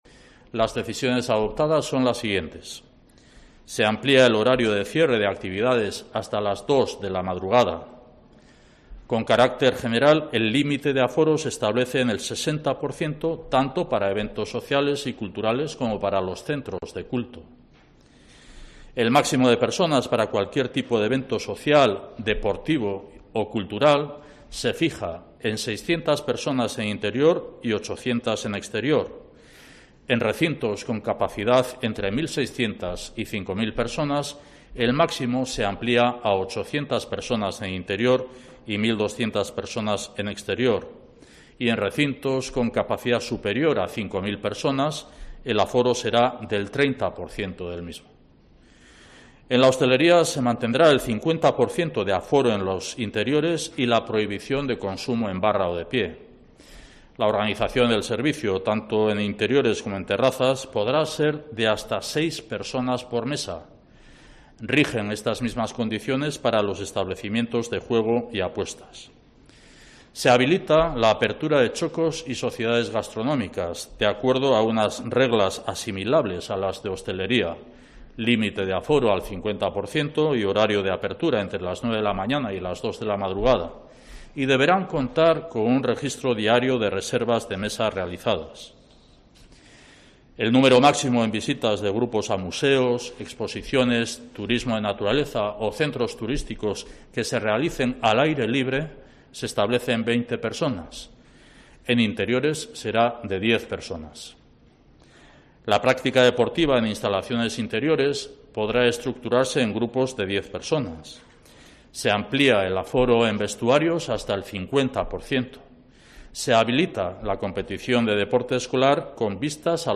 El lehendakari, Iñigo Urkullu, anuncia las medidas en vigor en Euskadi desde mañana